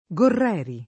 [ g orr $ ri ]